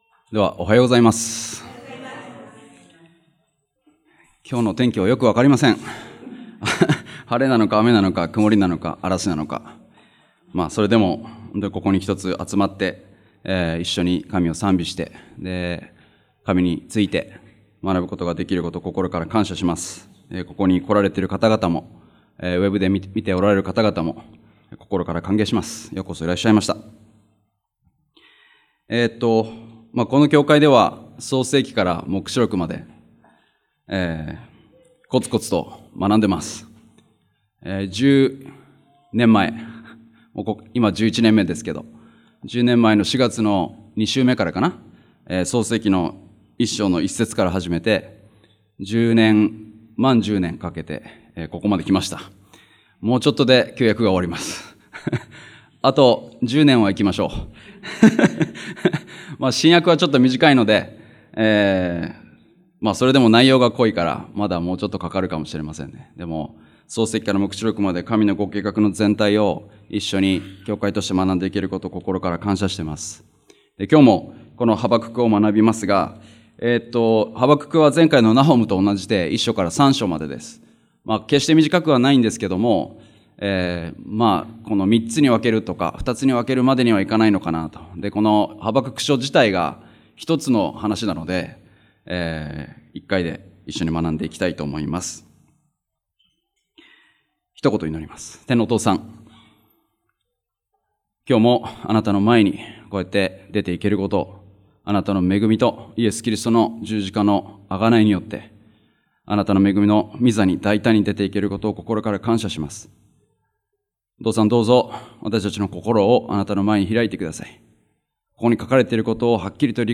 日曜礼拝：ハバクク書
礼拝やバイブル・スタディ等でのメッセージを聞くことができます。